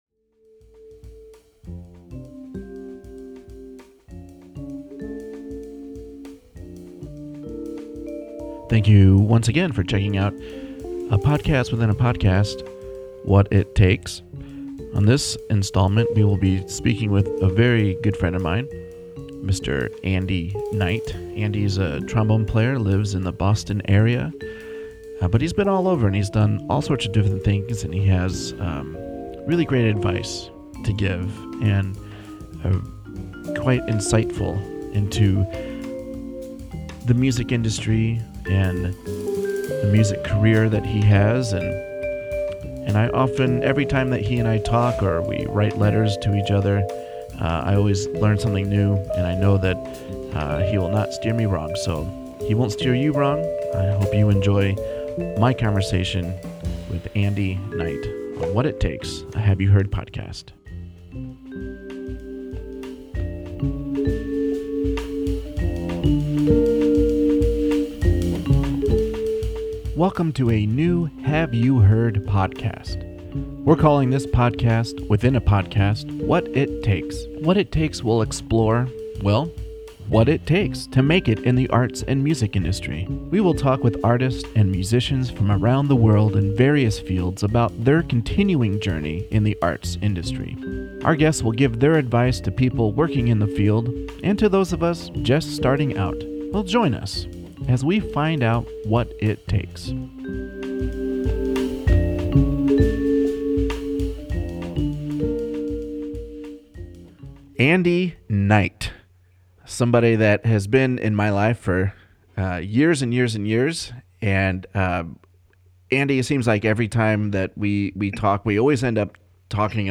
We generally tend to write (hand write, or old-fashioned typewrite) letters to each other, but for this installment of What It Takes, we upgraded to Skype. We basically talk as we usually do about life, family, having children, gigs, music, etc, etc.